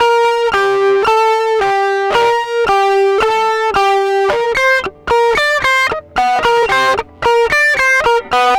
Track 15 - Guitar 09.wav